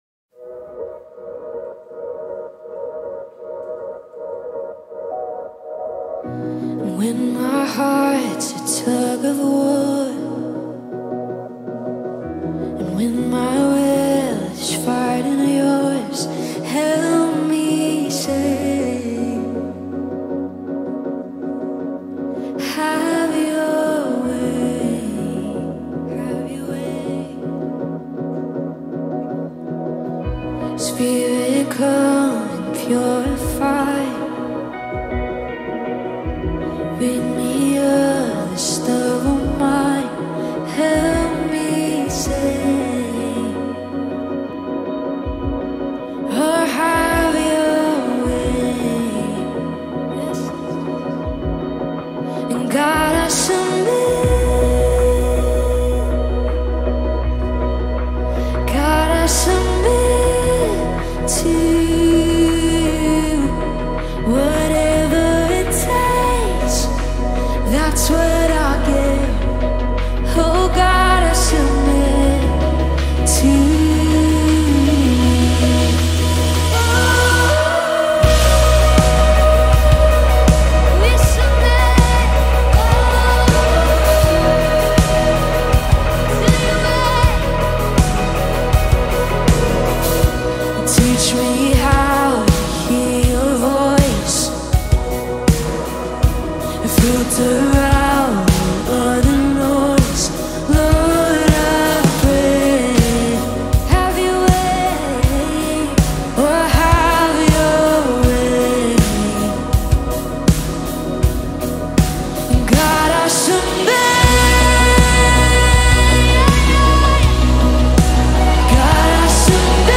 Original Key/Our Key (B)